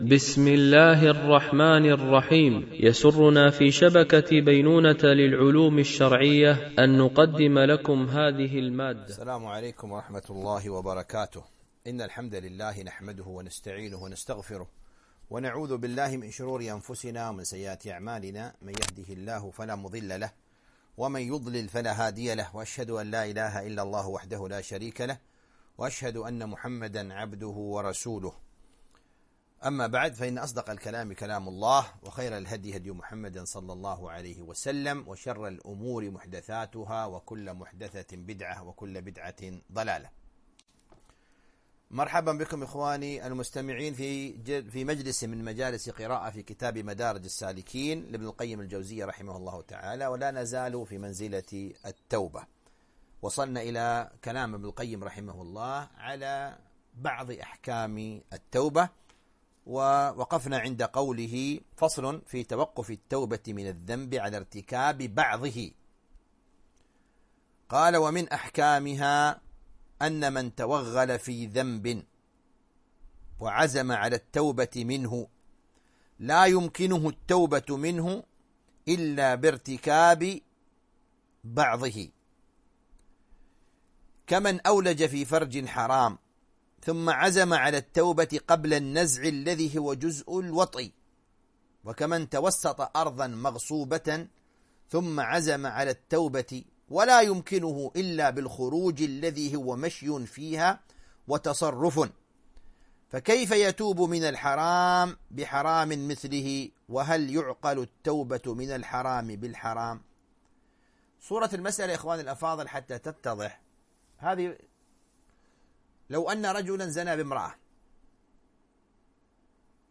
قراءة من كتاب مدارج السالكين - الدرس 32